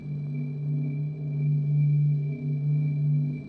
tank_skill_shieldbuff_loop.wav